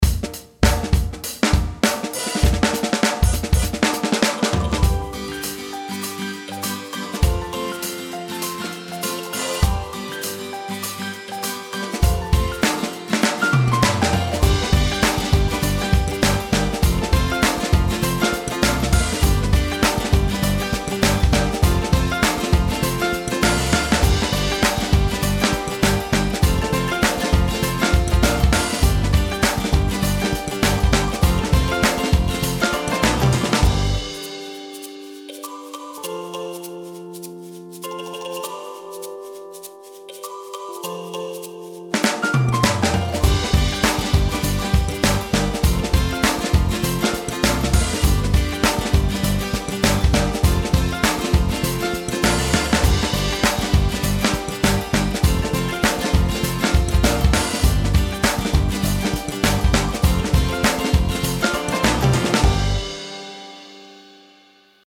Género:Música Infantil
Humor:Engraçado
Instrumento:Teclados
Duração: 1:04 • 100 BPM